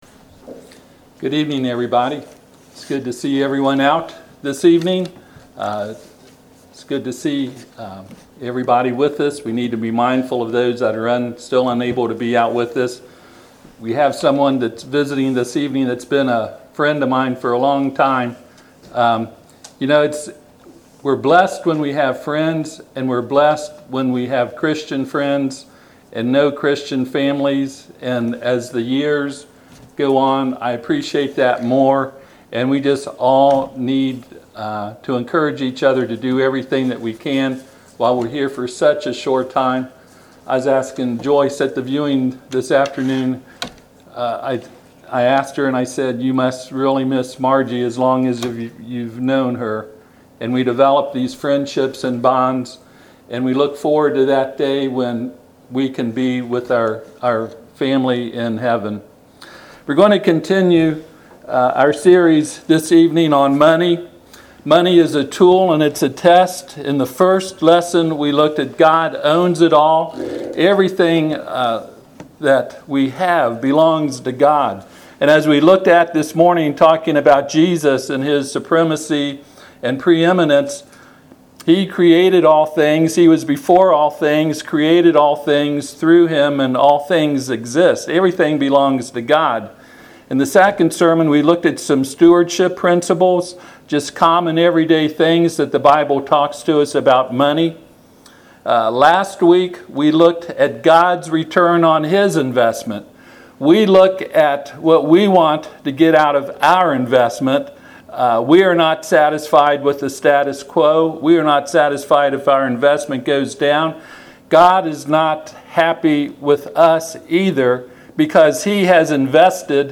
Hebrews 8:3-5 Service Type: Sunday PM God has always had a pattern for the work and worship of His people